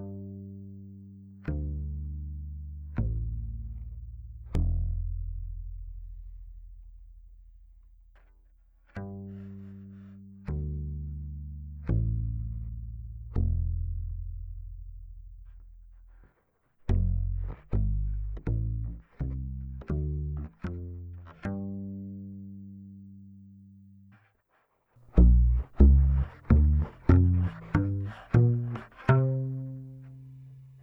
Il PU K&K ha minore sensibilità del HB ma un suono decisamente meno metallico.
Ascoltate in cuffia o con altoparlanti con buona estensione in basso, sono registrati a 44.1 kHz 24 bit, poi convertiti a 16 bit.
che bello! fa molto u-bass(anche l'aspetto delle corde) ma si sente anche che è un contra, un  suono che mi piace molto, preferisco il pickup originale però, ma quello è soggettivo direi
Con il K&K ciò non succede, e il K&K è più morbido.
PU_MaxBass_.wav